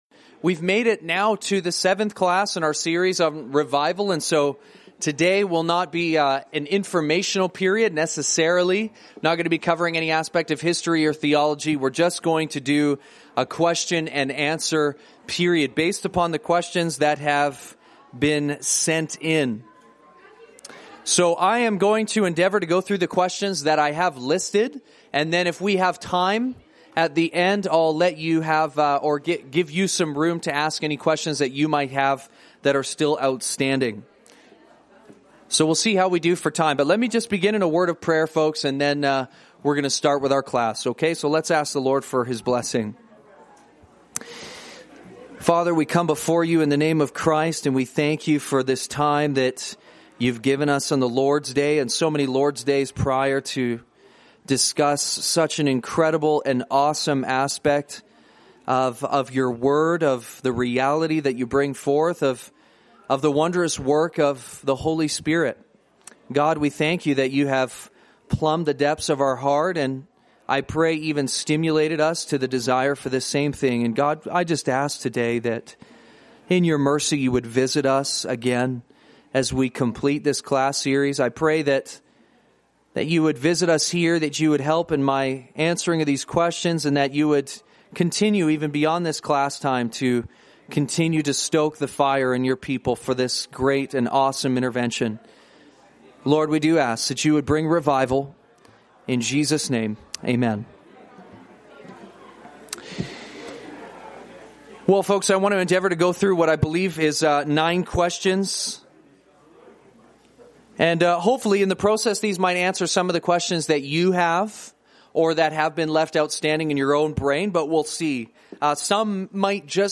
seminar on revival with a Q & A session